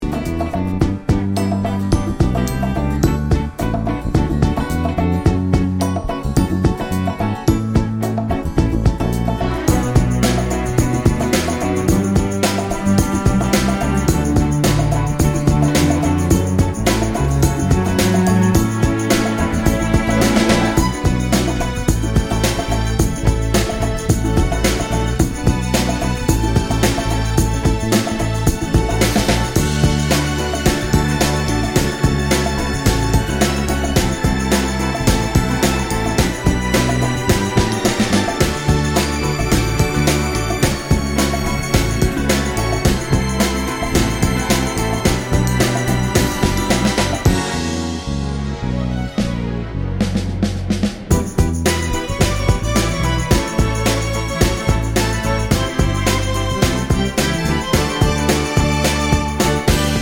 no Backing Vocals Soul / Motown 3:12 Buy £1.50